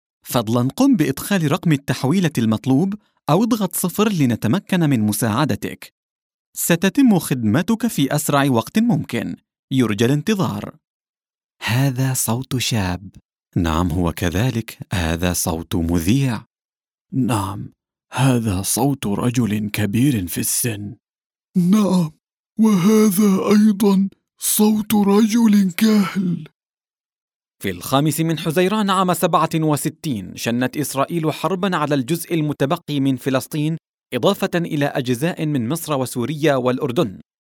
Arabian voiceover talent with warm and confident voice
Sprechprobe: Sonstiges (Muttersprache):